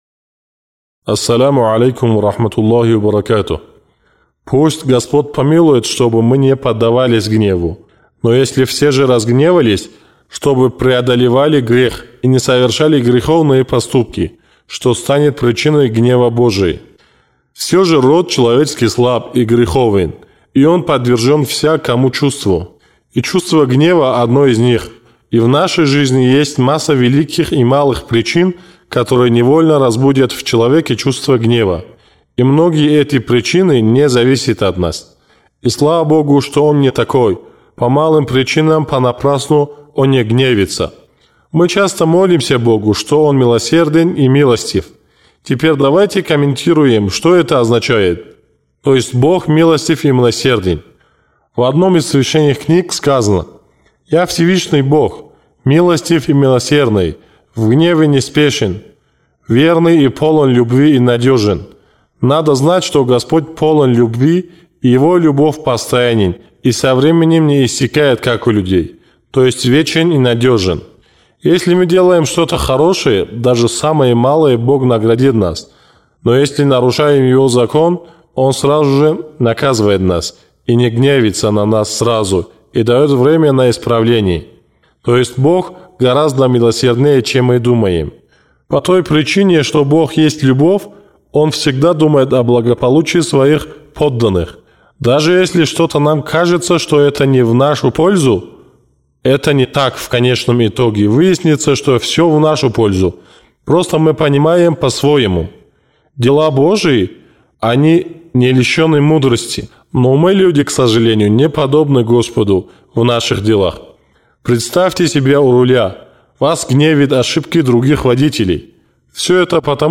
Что заставляет человека впадать в гнев? Как мы можем освободиться от зла нервозности? Чтобы найти ответы на все эти вопросы, вы можете прослушать пятую речь – «Избавление от гнева».